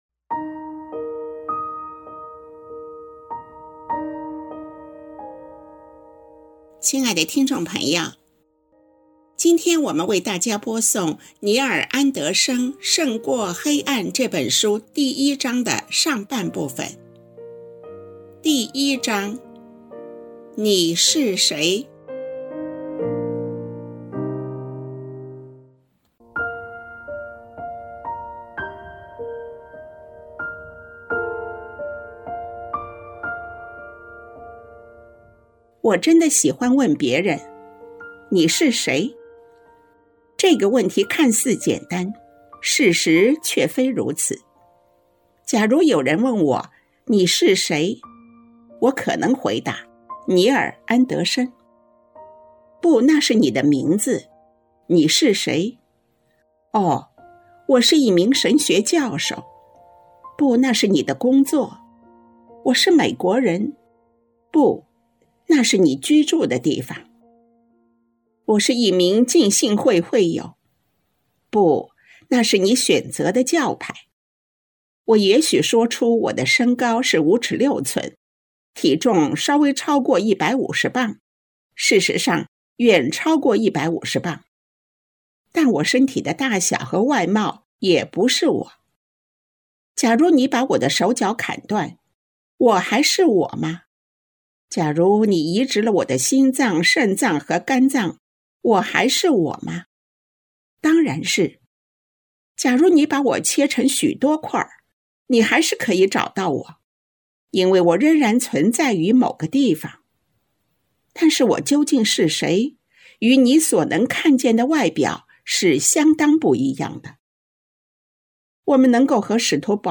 作者：尼尔·安德生 亲爱的听众朋友，今天我们为大家播诵 尼尔·安德生的《胜过黑暗》这本书。